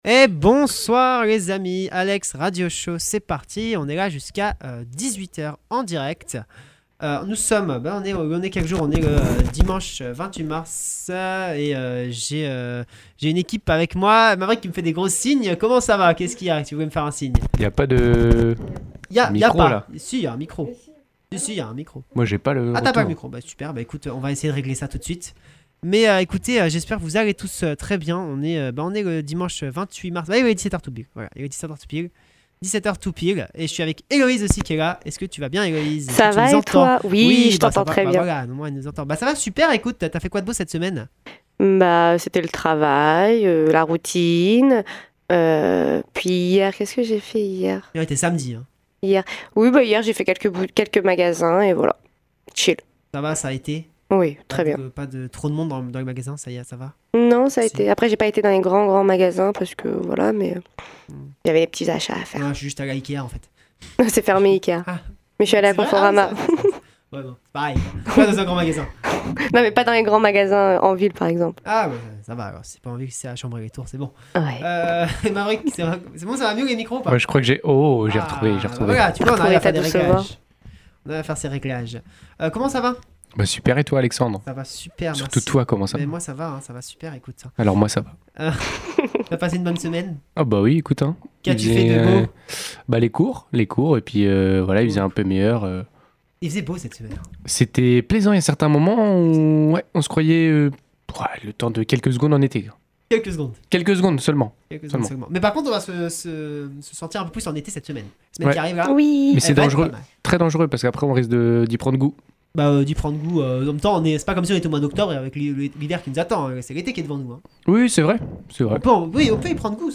Des musiques viendront rythmer le show toutes les 8 à 10 minutes. Nous retrouverons la météo en début d’émission et l’horoscope en milieu de celle-ci !